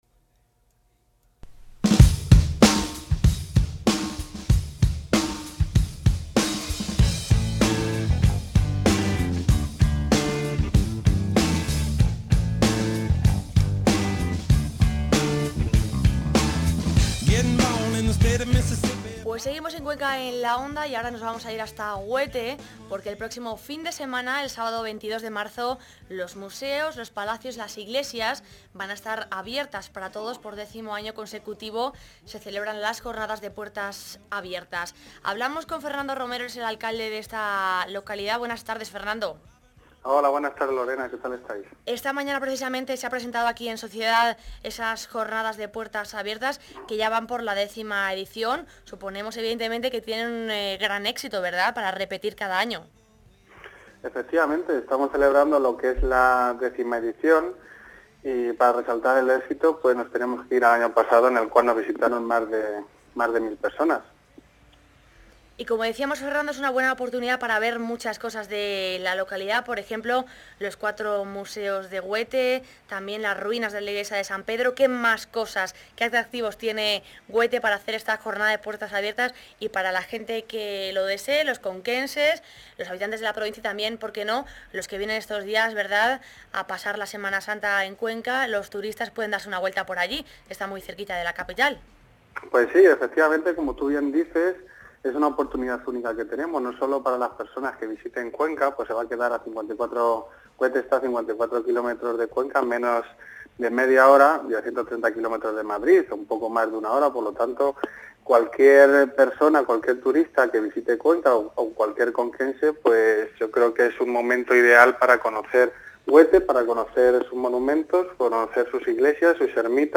Pincha para oir la entrevista al alcalde de Huete (1ª parte)